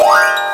Index of /m8-backup/M8/Samples/Fairlight CMI/IIX/PLUCKED
HARPUPGD.WAV